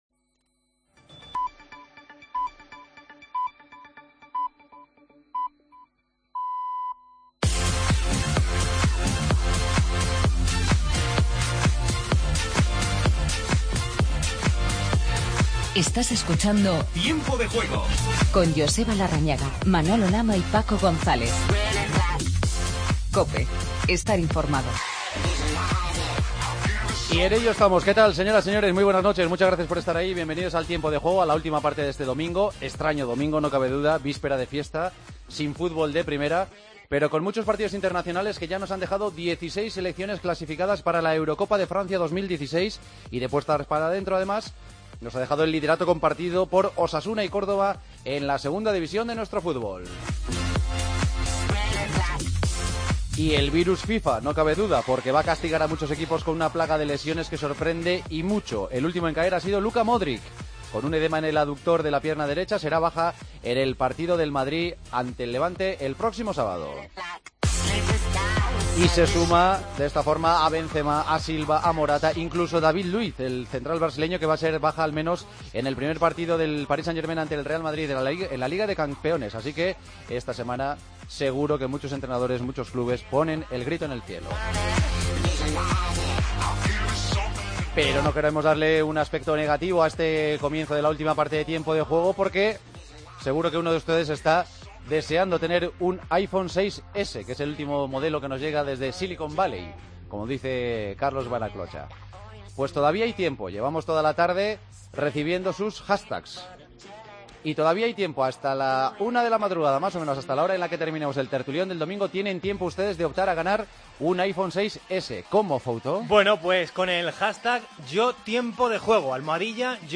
Entrevistas a José Luis Oltra y Enrique Martín, técnicos de Córdoba y Osasuna.